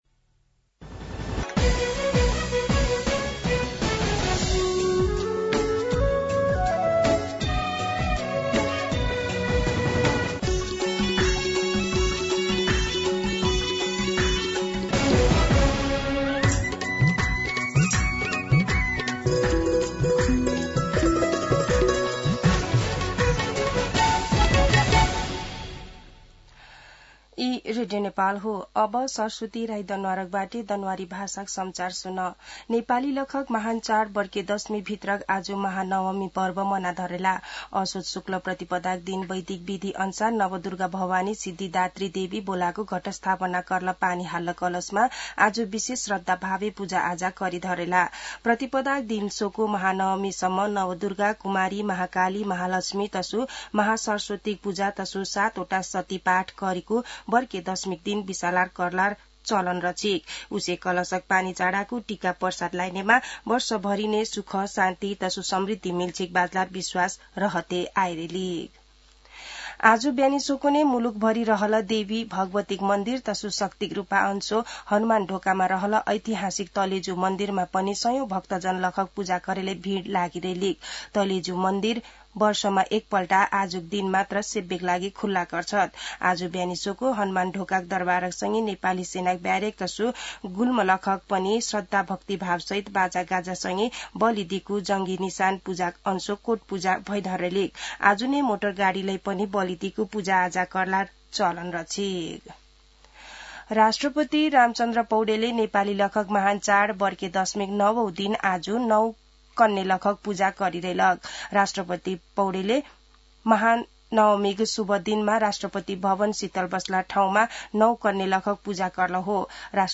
दनुवार भाषामा समाचार : १५ असोज , २०८२
danuhar-News.mp3